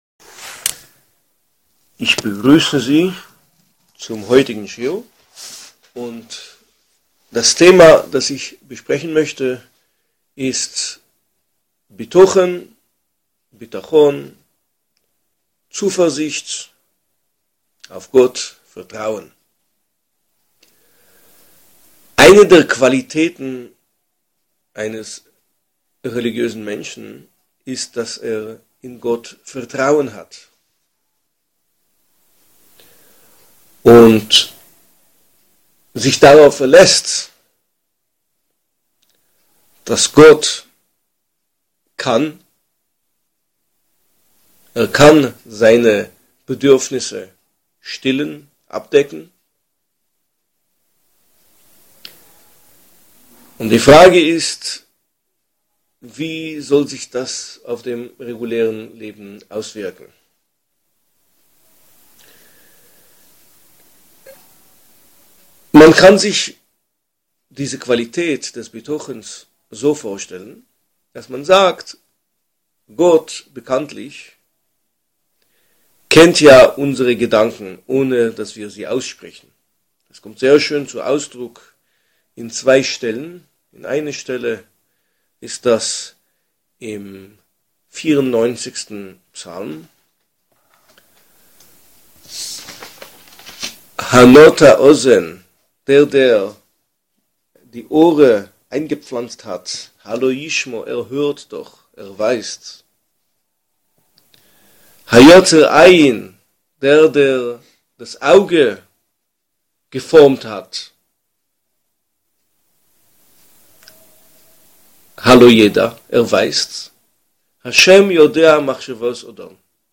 Neuer Audio-Schiúr: Was ist Bitachon / Zuversicht in G”tt?